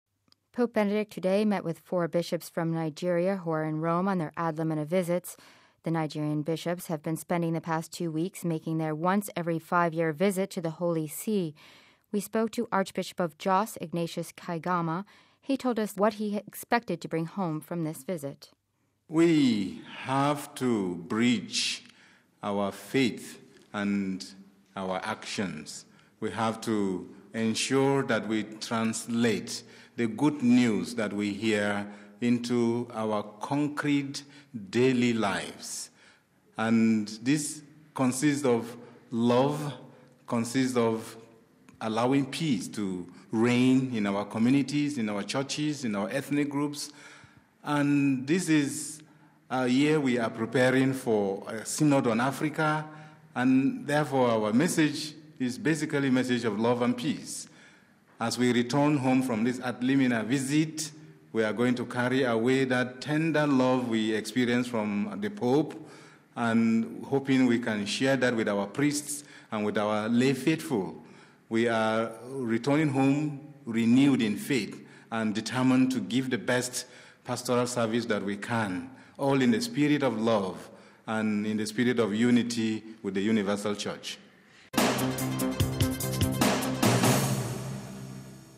We spoke to Bishop Archbishop of Jos, Ignatius Kaigama. He told us what he expected to bring home from this visit.